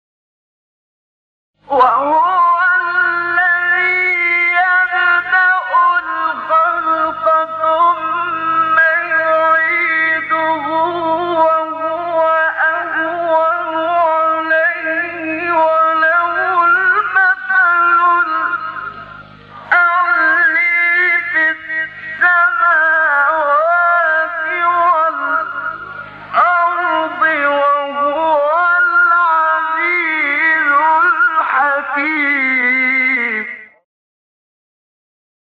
سایت قرآن کلام نورانی - چهارگاه منشاوی.mp3
سایت-قرآن-کلام-نورانی-چهارگاه-منشاوی.mp3